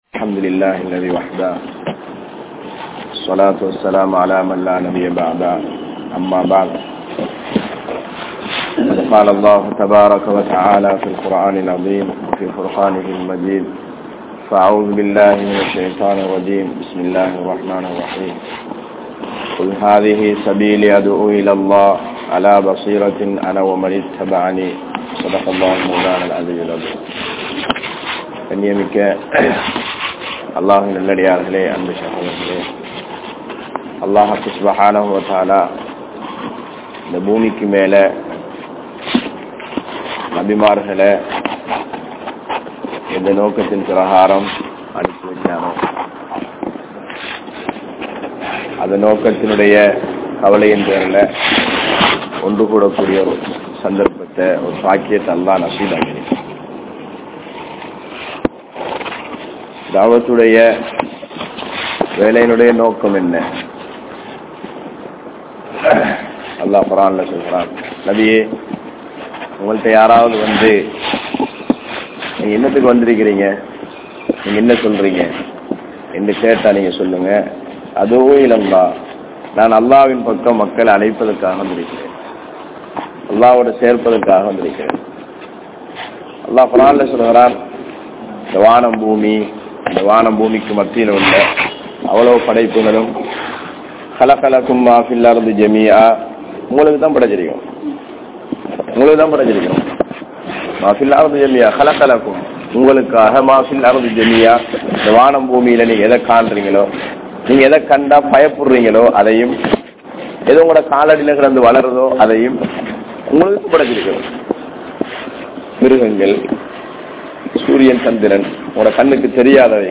Mathurankadawela Jumua Masjidh